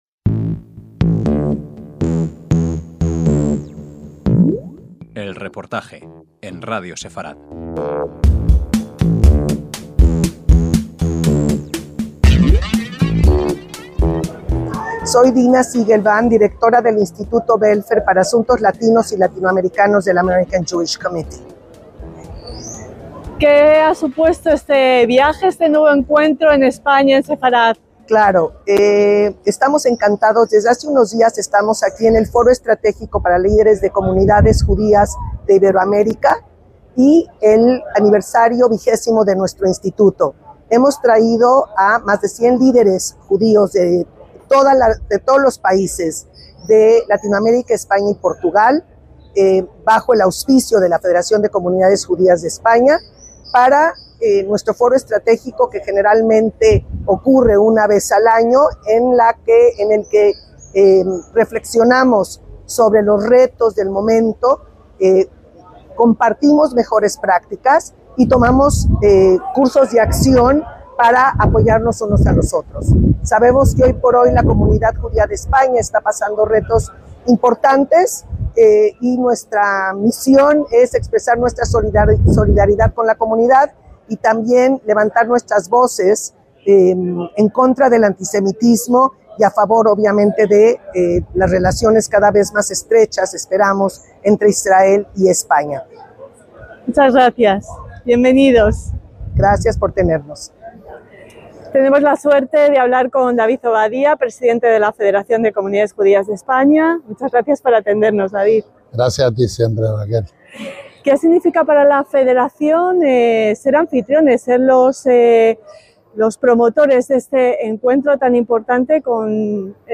EL REPORTAJE - El Foro Estratégico para Líderes de Comunidades Judías Iberoamericanas (AJC) que ha tenido lugar en España bajo el auspicio de la FCJE participó en la conmemoración en la sinagoga-iglesia de Santa María la Blanca de Toledo el sexagésimo aniversario de la Declaración Nostra Etate hito en las relaciones judeocristianas.